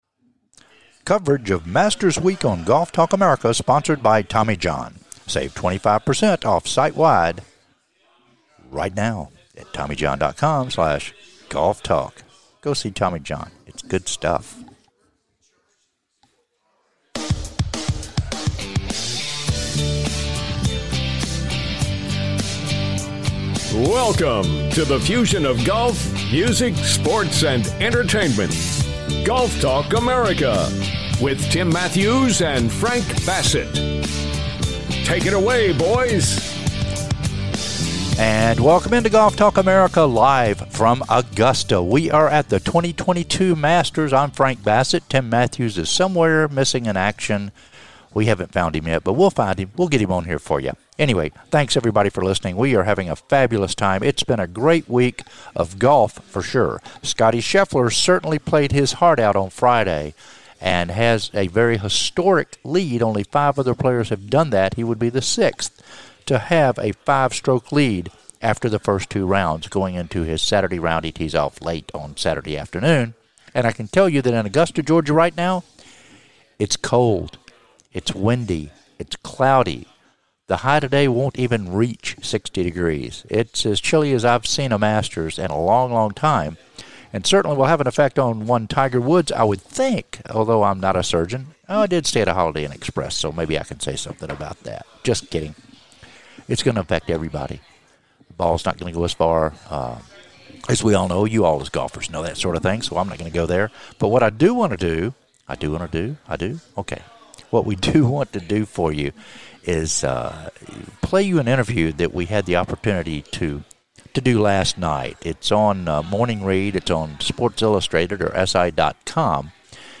"LIVE" FROM THE MASTERS